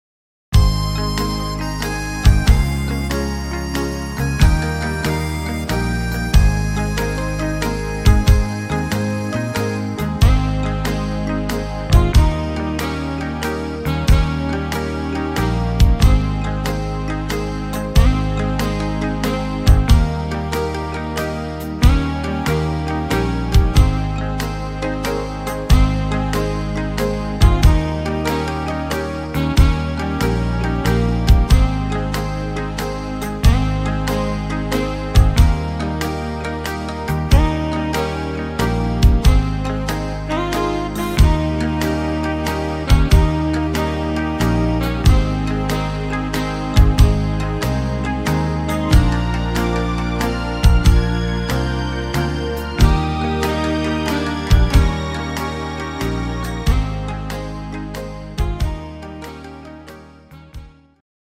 instr. Sax